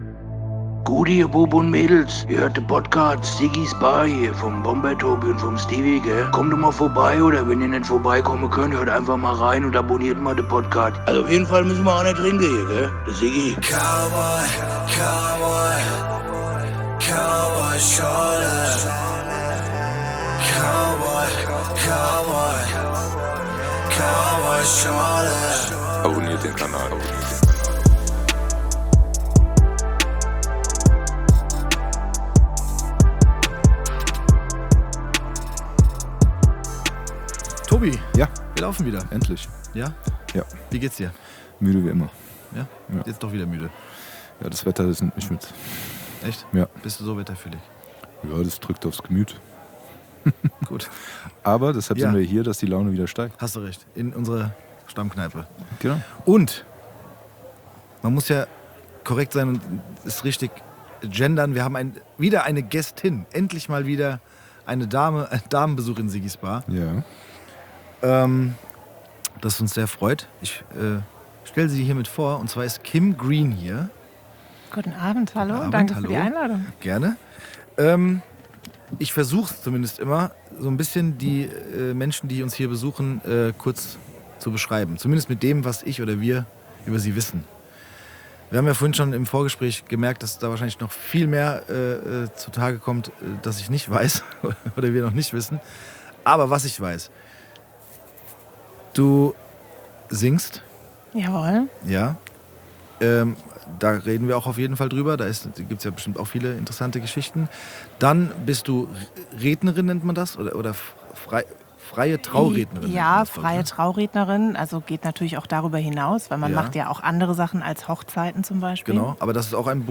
Heute ist Damenbesuch zu Gast in Siggi´s Bar.